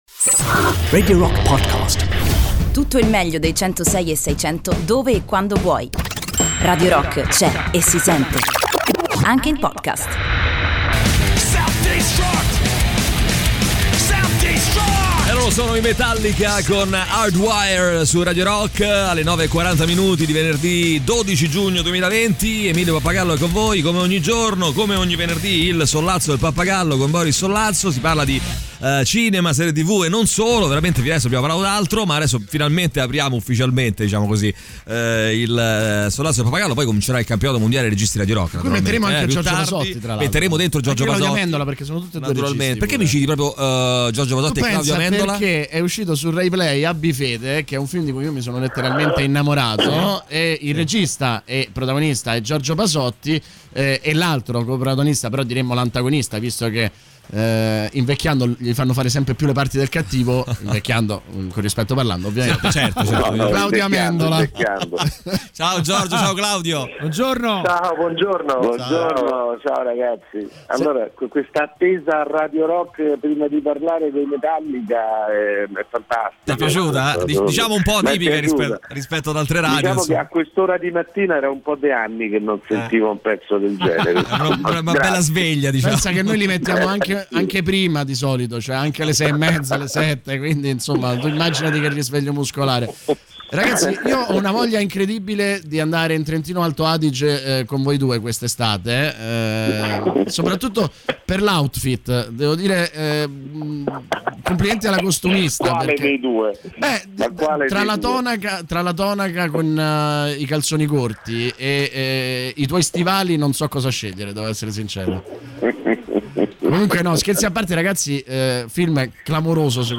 in collegamento telefonico con Claudio Amendola e Giorgio Pasotti